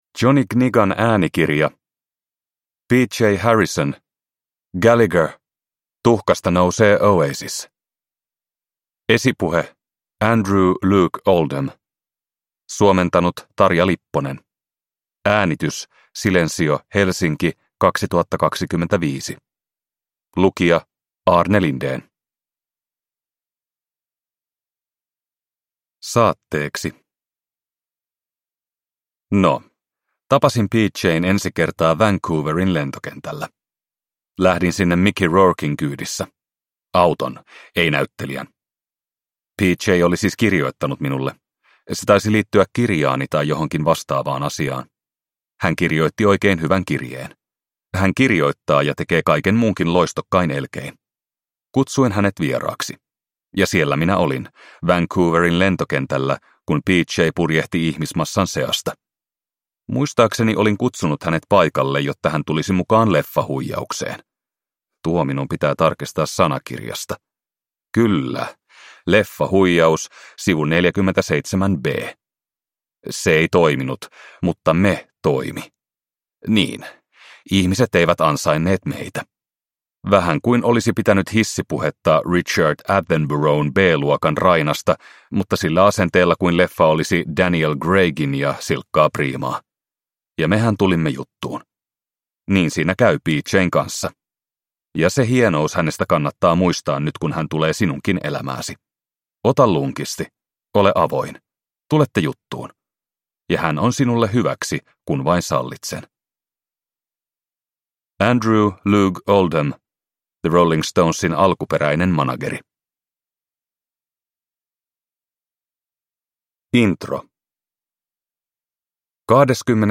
Gallagher – Tuhkasta nousee Oasis – Ljudbok